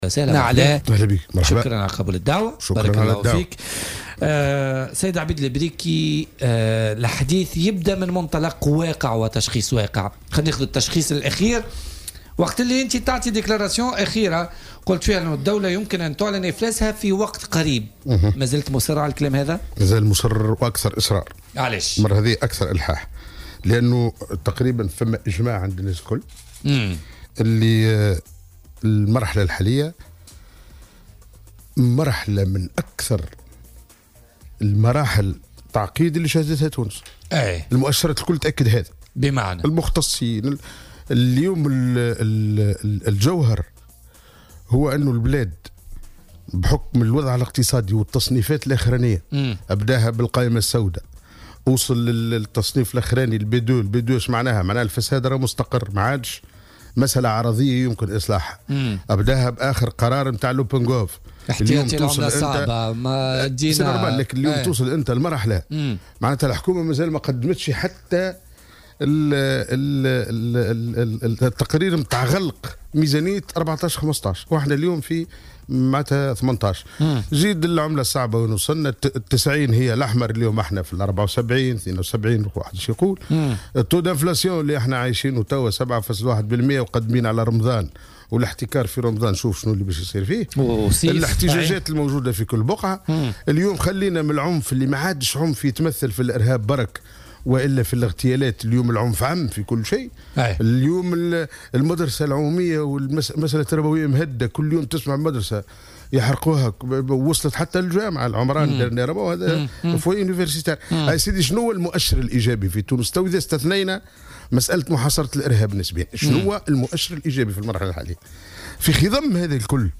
وأضاف ضيف "بوليتيكا" على "الجوهرة أف أم" أن تونس تمرّ بالمرحلة الأكثر تعقيدا انطلاقا من مؤشرات تم تسجيلها.